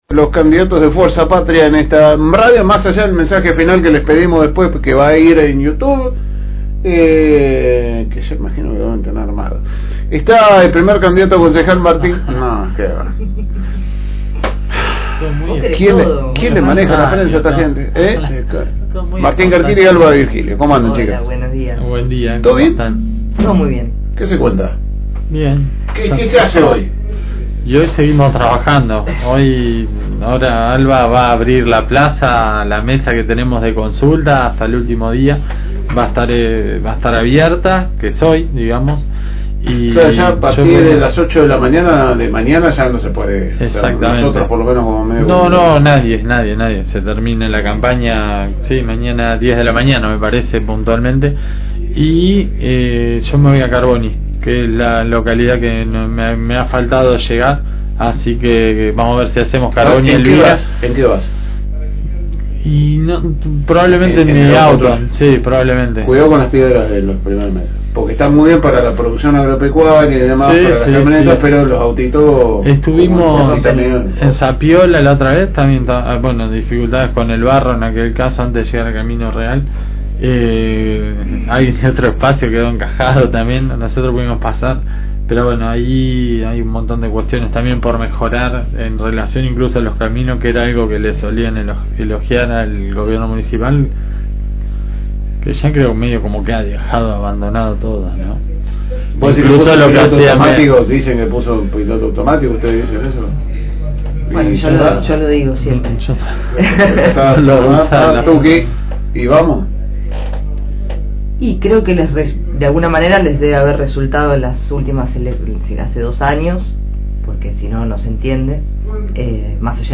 Por última vez pasaron por los estudios de la FM Reencuentro los candidatos de Fuerza Patria Lobos.